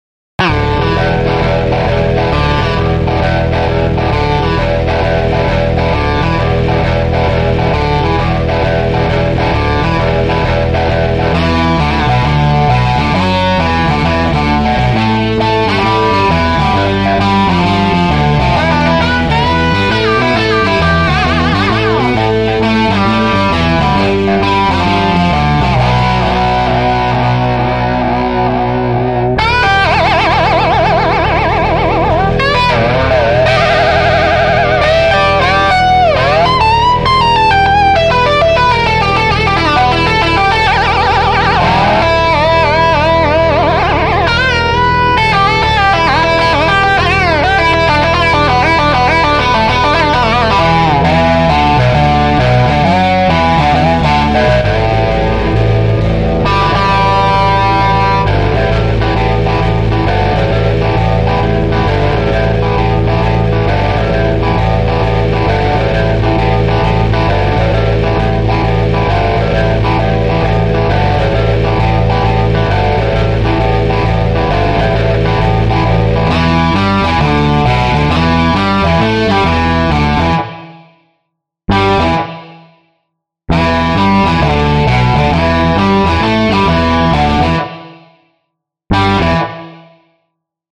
●LesPaulP90●Telecaster